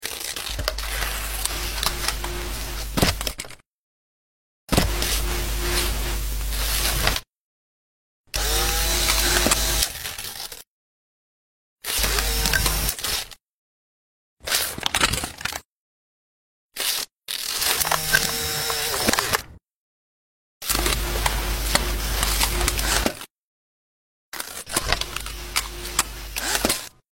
Tearing Old Film Overlay! Sound Effects Free Download